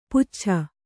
♪ puccha